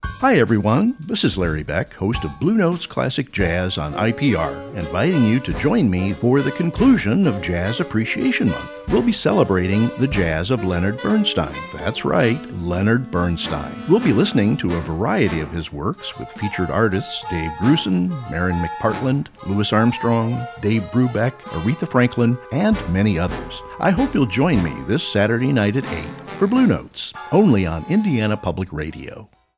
Bluenotes-for-Apr-2026-Web-Promo.mp3